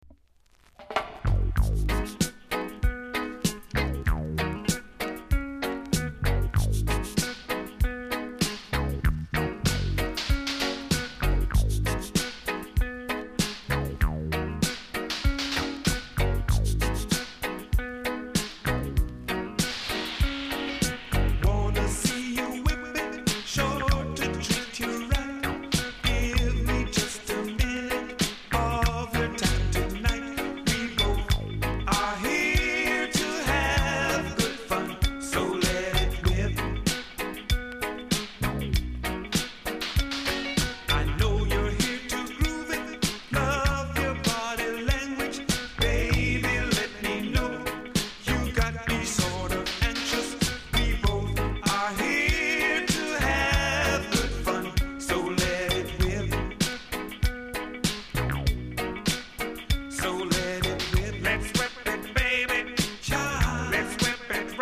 ※小さなチリノイズが少しあります。
RARE ISLAND DISCO!!